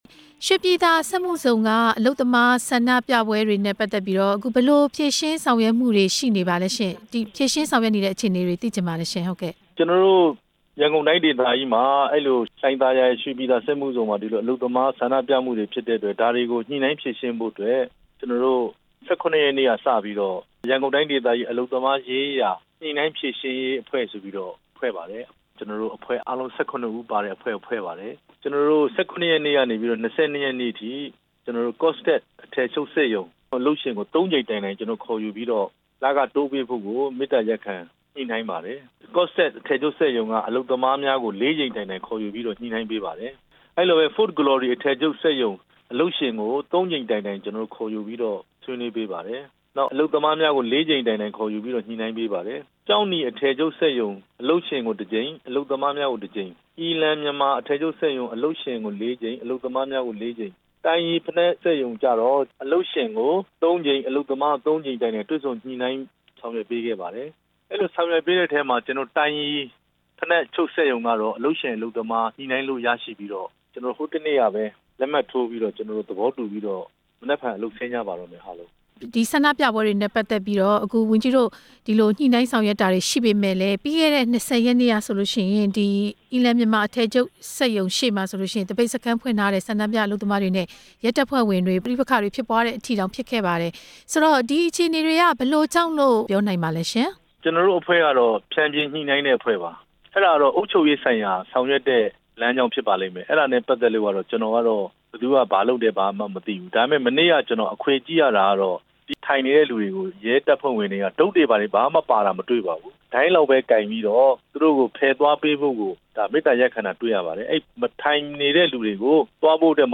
အလုပ်သမားဆန္ဒပြပွဲတွေ အကြောင်း အလုပ်သမားရေးရာ ဝန်ကြီးနဲ့ မေးမြန်းချက်